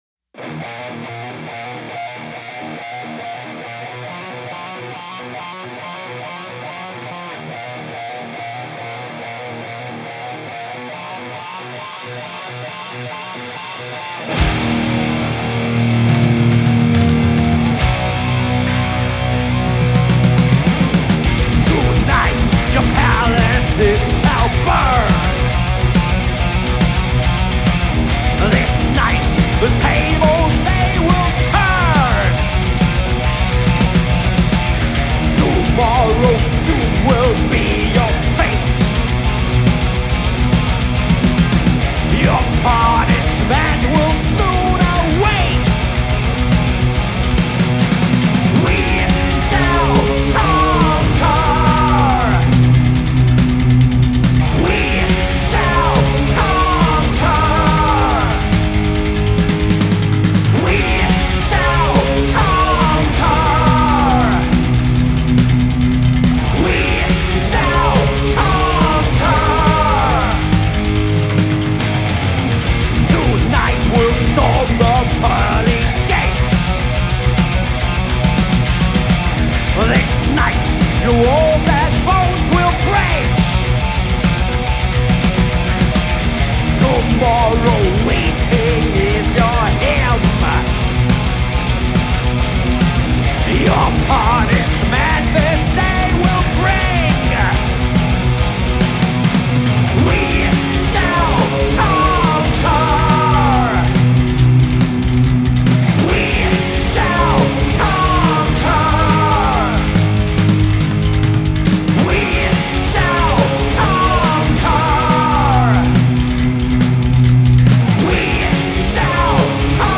Thrash Metal/Hardcore/Crossover